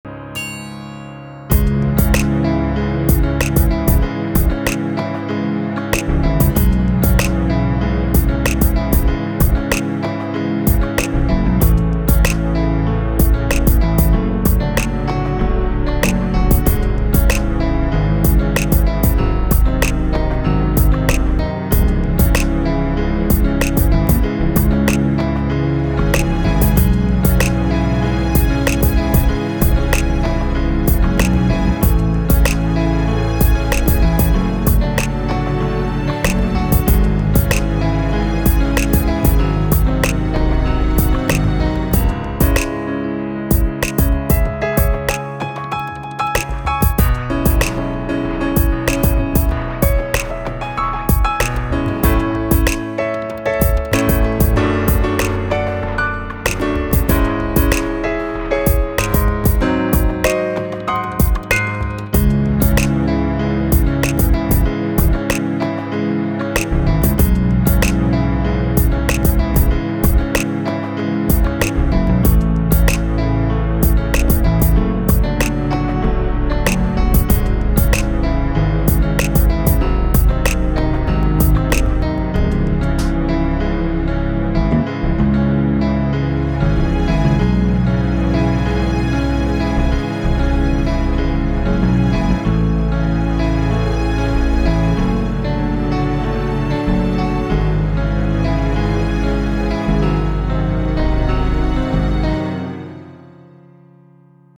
with a beat…